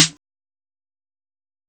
Waka SNARE ROLL PATTERN (50).wav